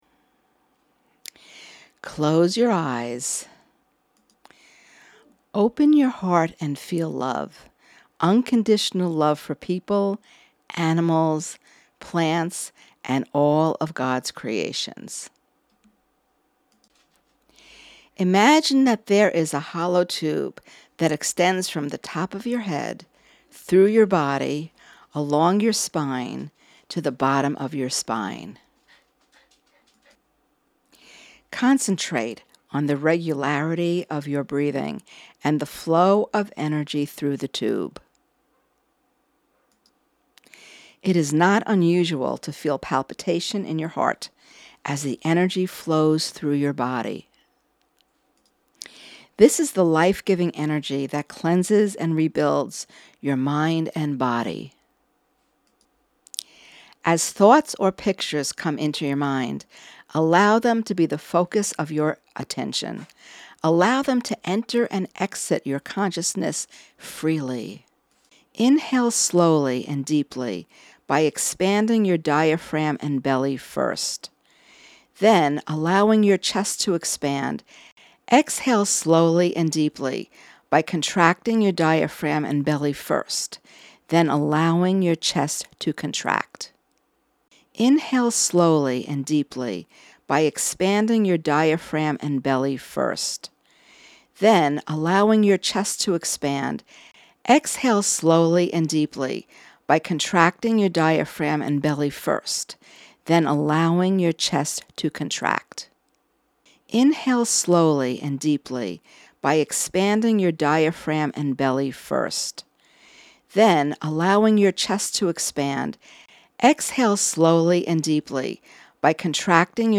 Essential Health - Genesis Guided Meditation
Genesis-Meditation.mp3